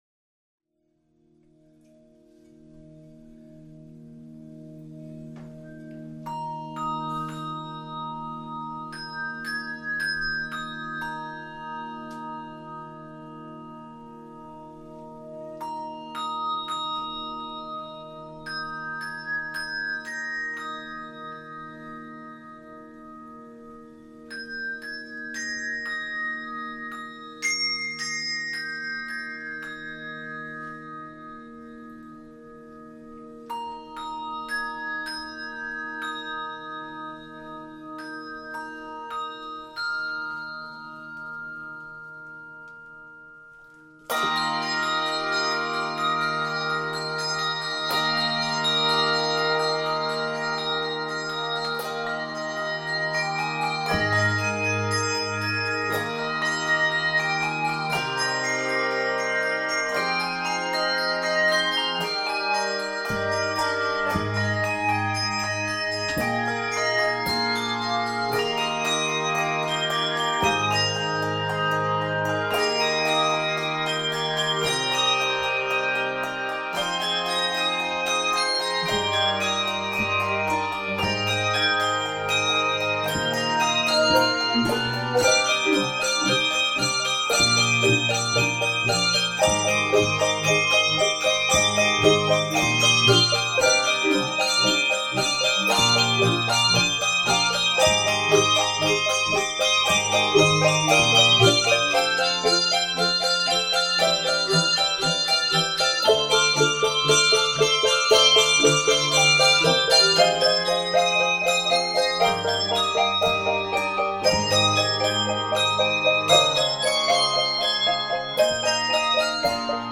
So I play handbells, and I'm going to handbell festival in April, so I thought I'd share one of my very favorite pieces that we're playing at festival this year. It's called the Storm shall Pass. and the reason that it's my favorite piece is because there are so, so many different handbell techniques featured in this piece, and that's what makes it so much fun to play.